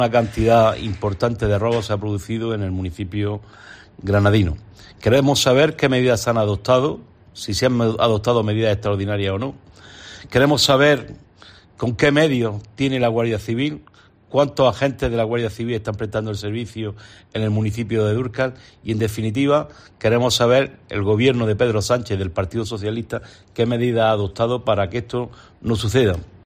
Jose Robles, senador del PP